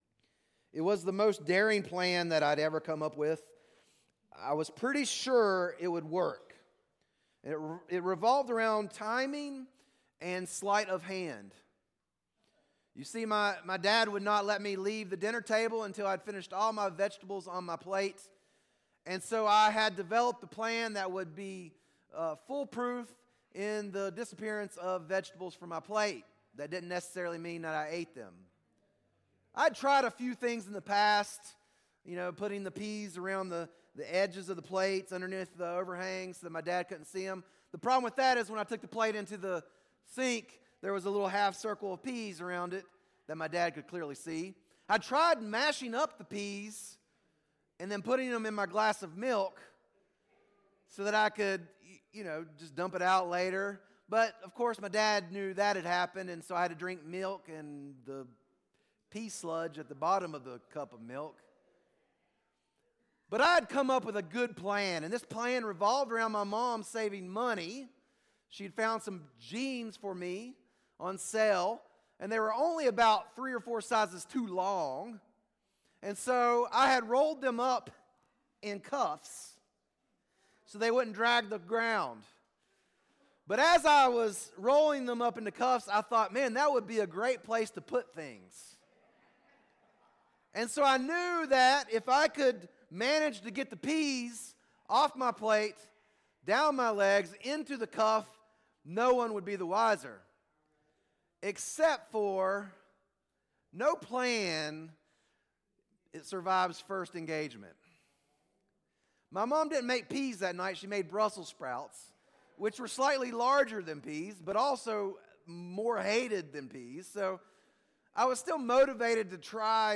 Sermons | Scranton Road Bible Church